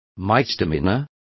Complete with pronunciation of the translation of misdemeanors.